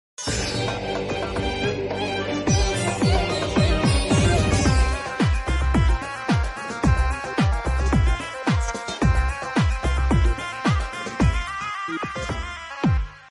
the melody of the song shifts to Egyptian when she comes on